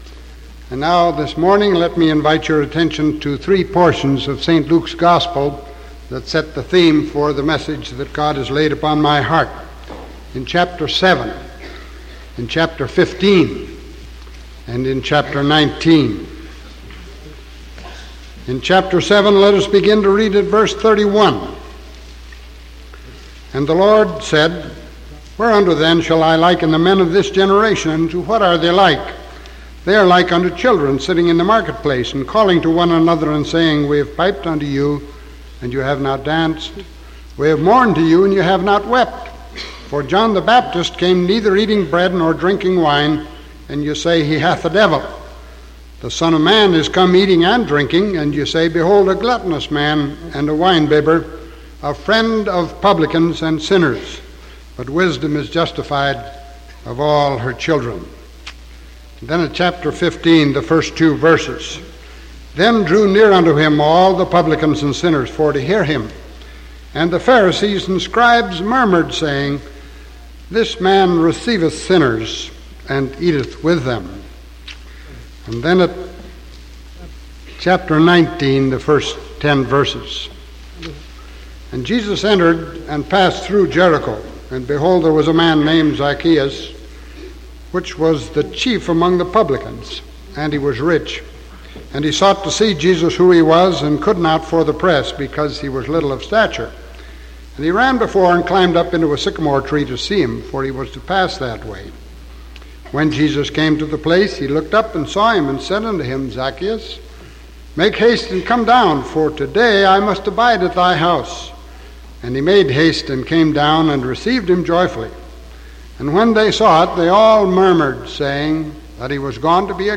Sermon October 27th 1974 AM